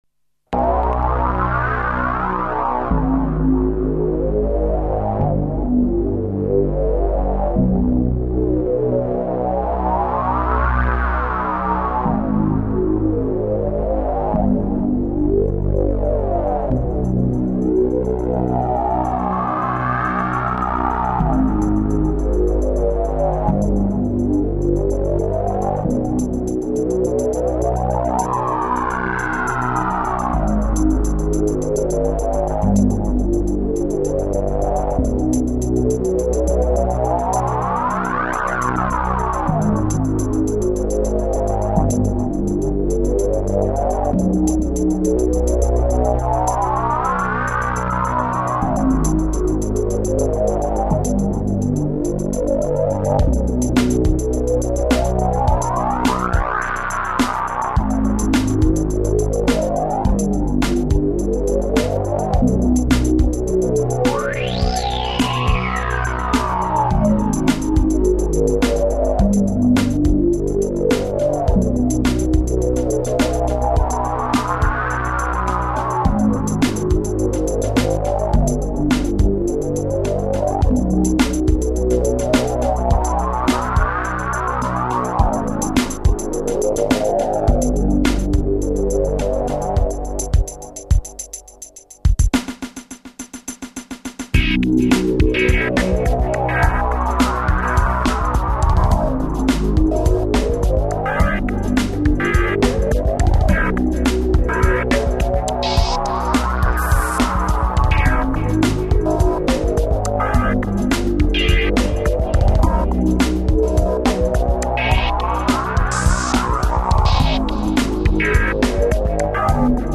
Between 1998 and 2008 I did some electronic livesets.